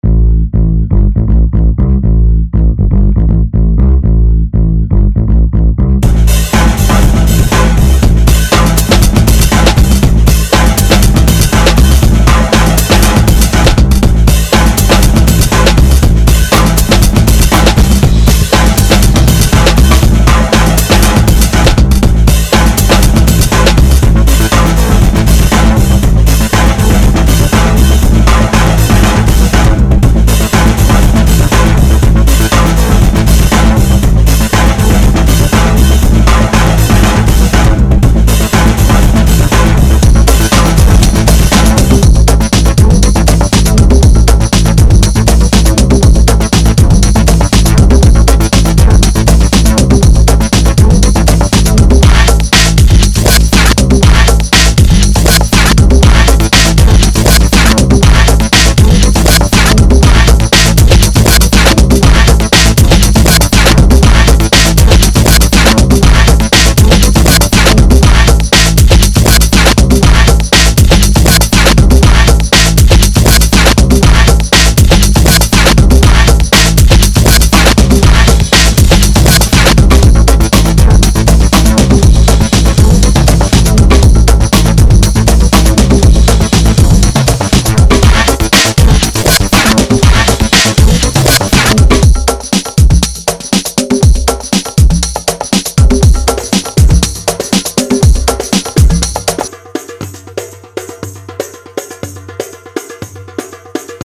dance/electronic
Techno
Breaks & beats
Trance